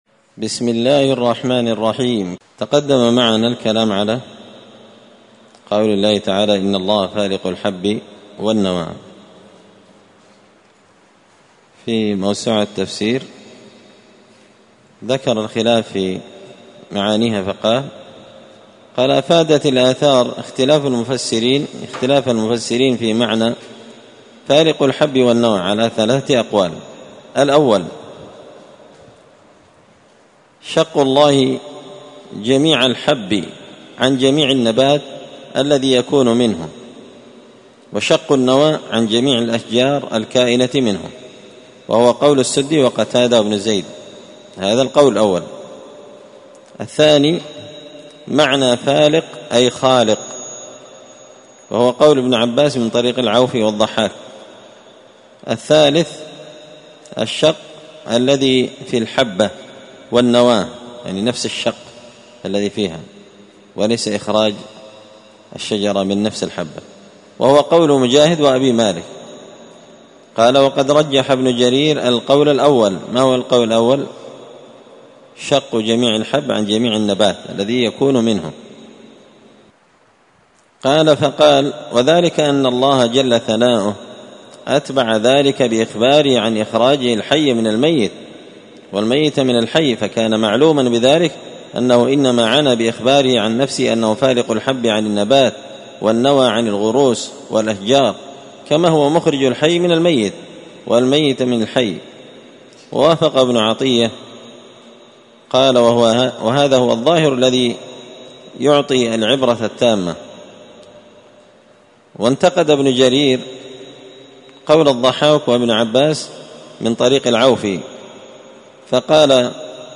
مسجد الفرقان قشن_المهرة_اليمن
مختصر تفسير الإمام البغوي رحمه الله الدرس 334